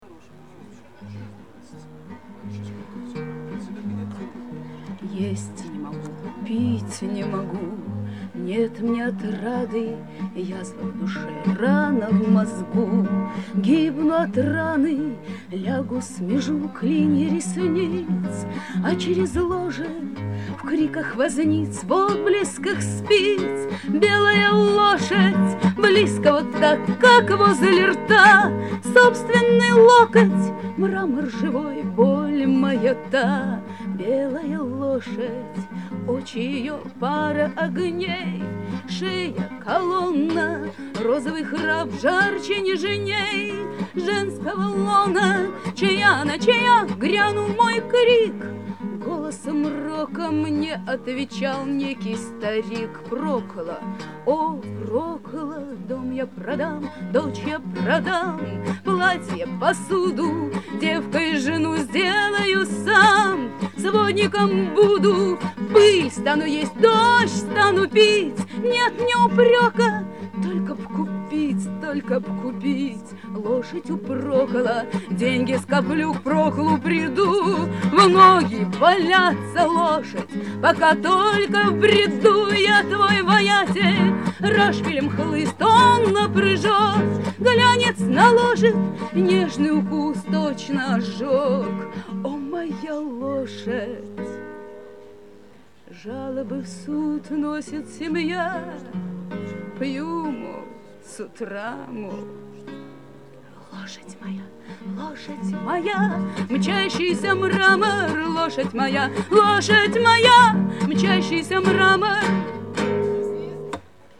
Запись фрагмента Шляпной Мастерской в лагере ТА "32-е августа" на Грушинском-2002